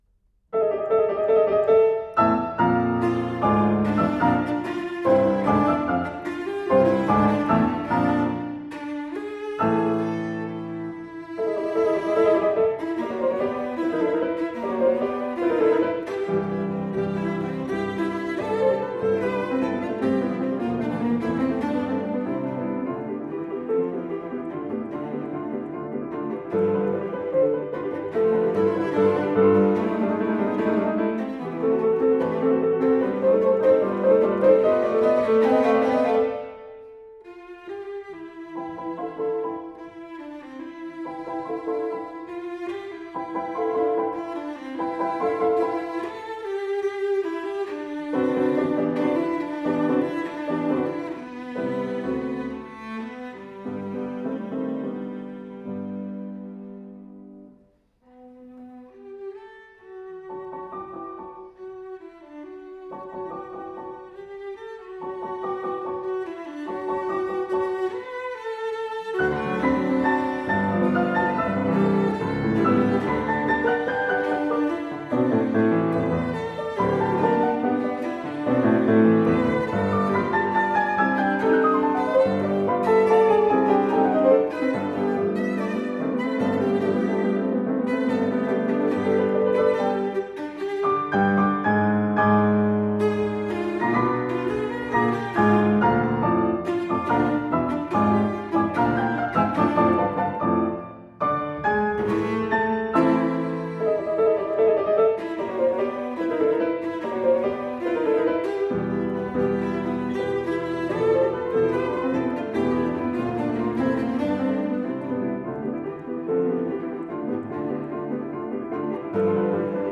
For Cello and Piano